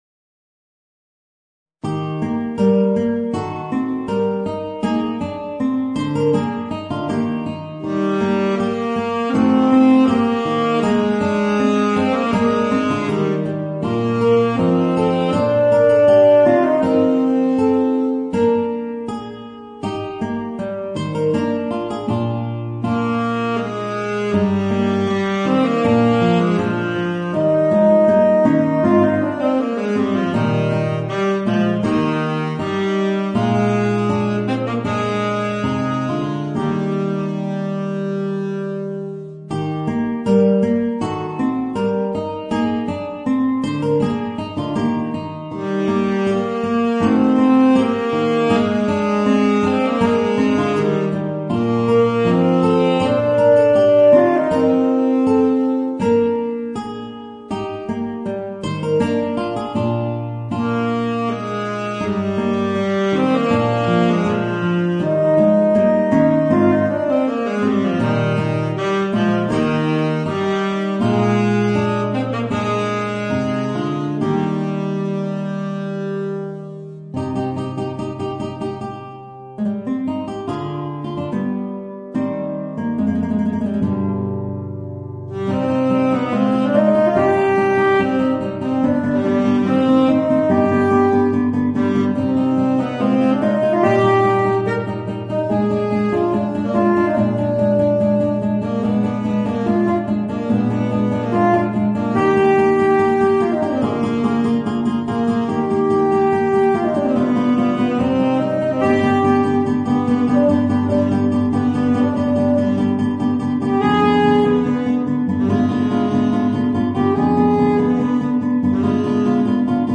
Gitarre & Tenorsaxophon